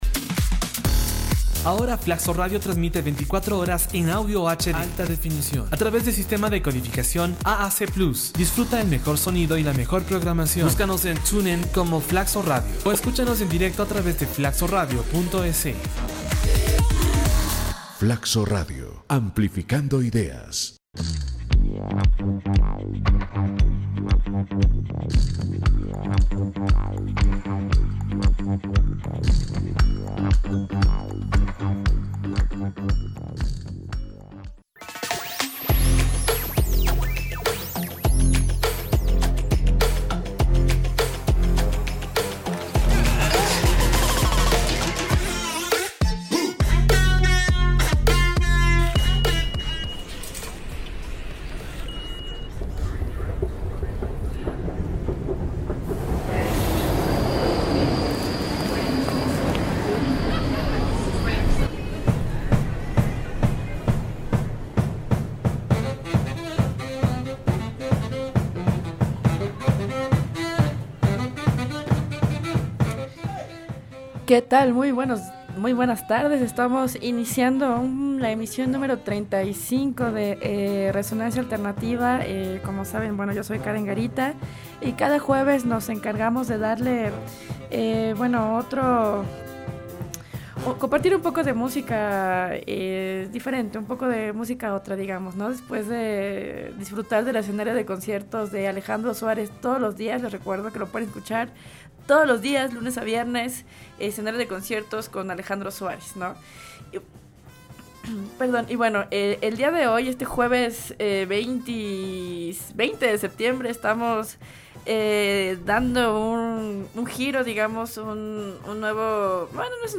Techno Band | FLACSO Radio
Te invitamos a escuchar algunos temas del subgénero tecno banda, una mezcla de tambores, trompetas y trombones de orquesta con un toque electrónico. El resultado cautiva la traslación de la electrónica en clave acústica, pero con instrumentos de percusión y viento-metal.